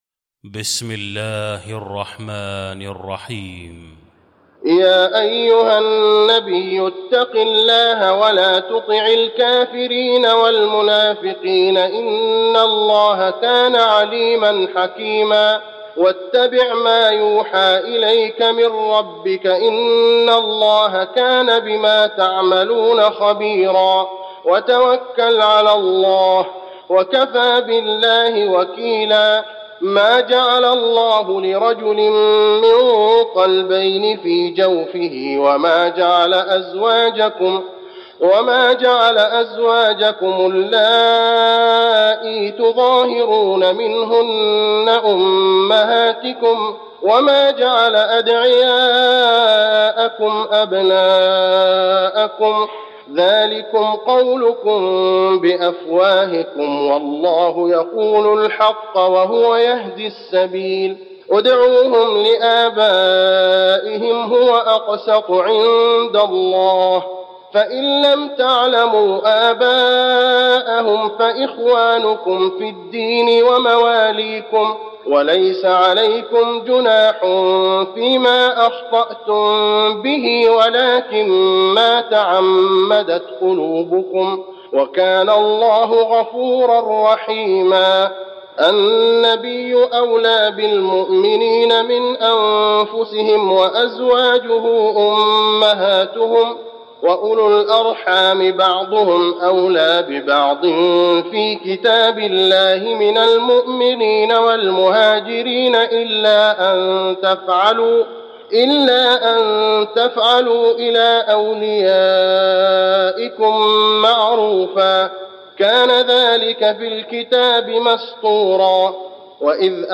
المكان: المسجد النبوي الأحزاب